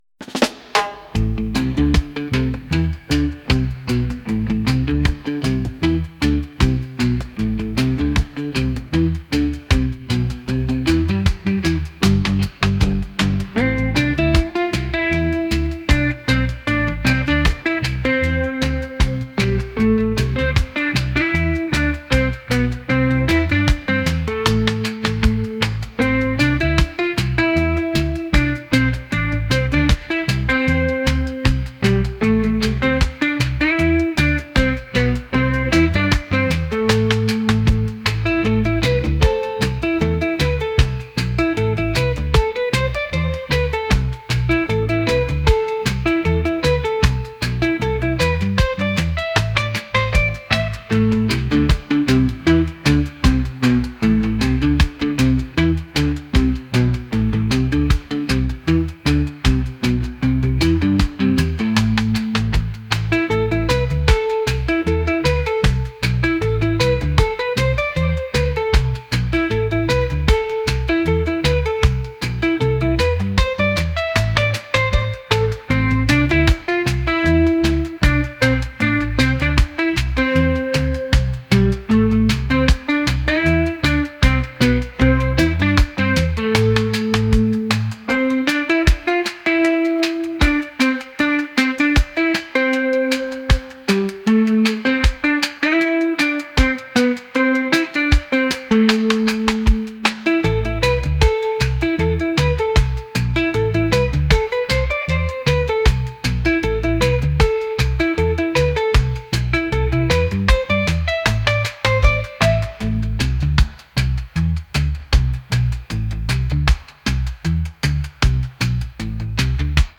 reggae | rock